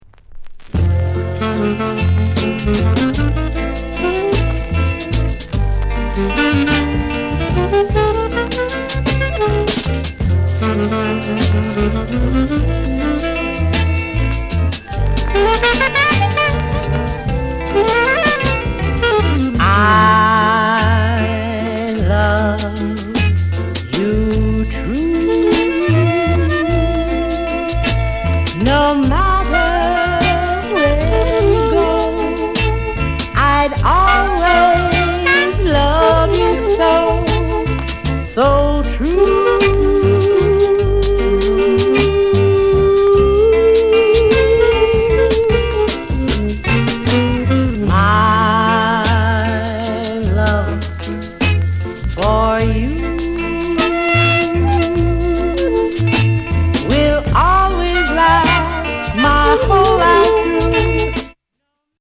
SOUND CONDITION A SIDE VG(OK)
ROCKSTEADY